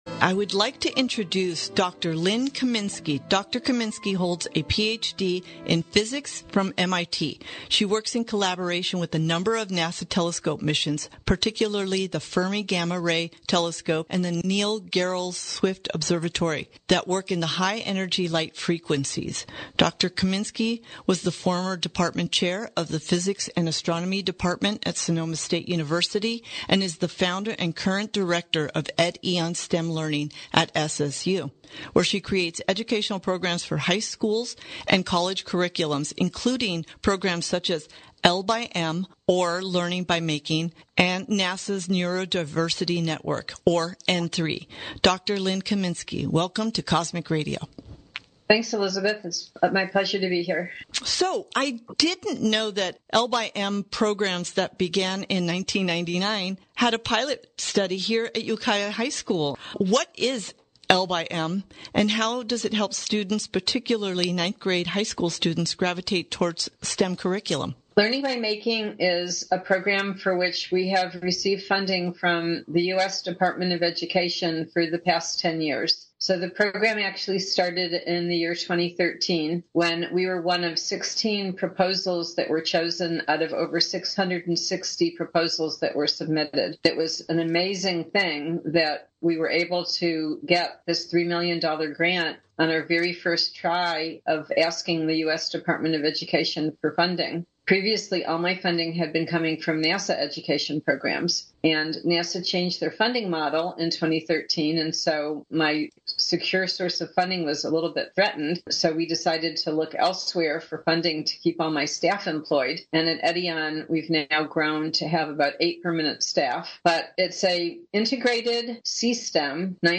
Cosmic Radio Interview KZYX Radio